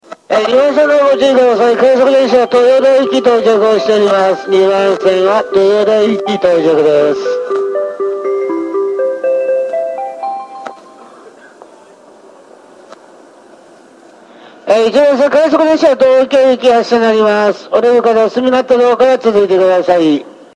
発車メロディーフルコーラスです。朝ラッシュ時が最も鳴りやすいですが、駅員放送と非常に被りやすいです。２コーラス目にもたまに入ります。